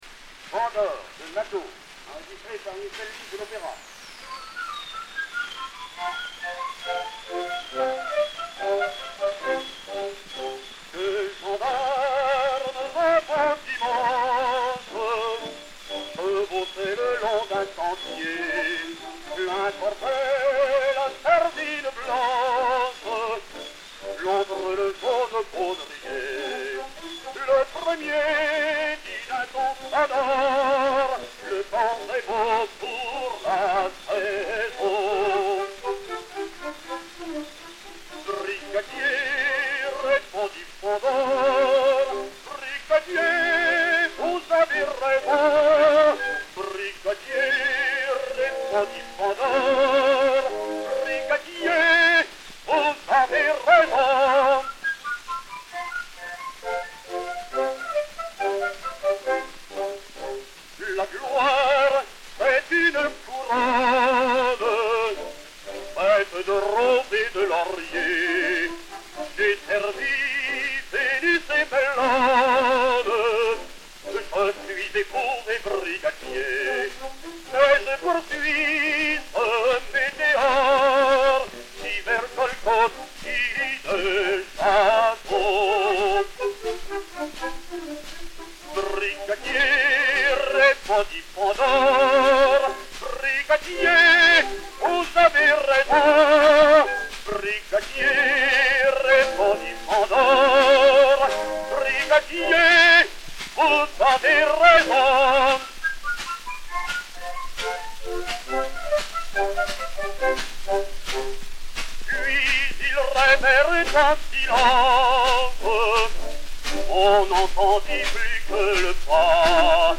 Orchestre
enr. vers 1912 [incomplet]